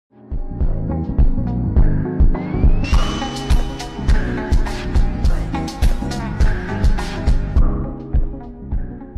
the lil sonic sprint noise rlly puts the cherry on top